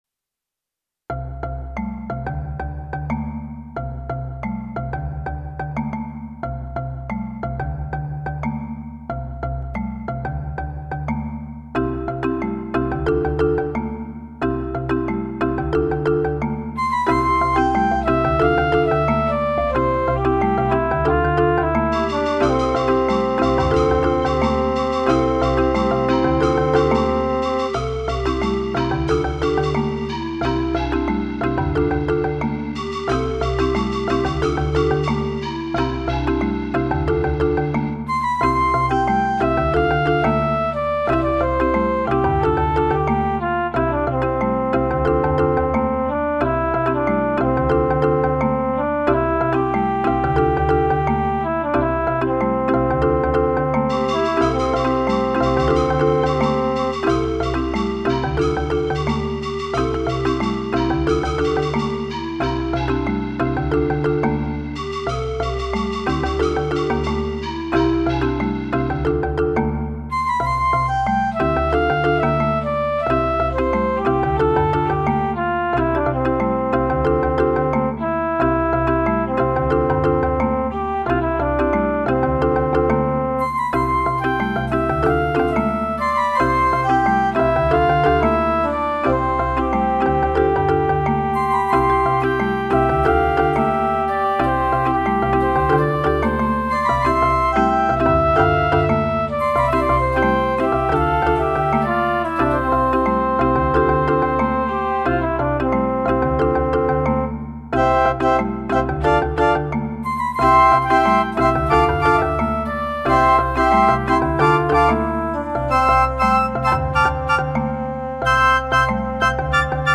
It was here that we decided that in the interest of time the children would play non-tuned percussion instruments (adding an African sound) and I would create computerized accompaniments.
Accompaniment, MP3  Listen to the audio file